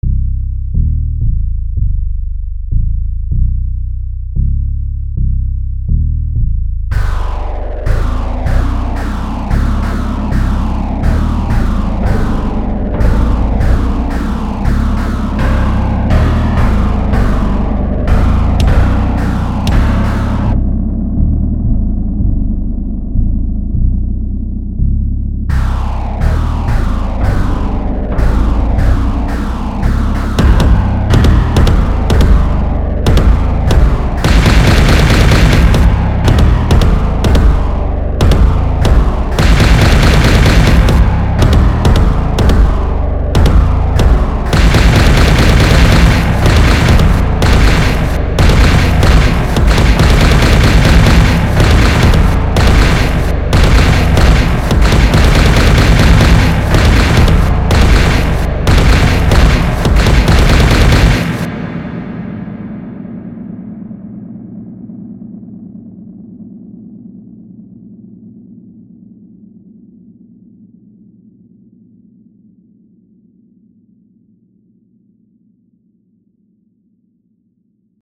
Aggressive music [mp3] | Project Perfect Mod
Well, my usual ambient stuff wouldn't work, so I started practicing on fast agressive music.
Very. some tracks seem to have messed up endings like a long roll-out or a sample that keeps running, don't worry about it
The rest aren't really aggresive, they're kind of dark ambient.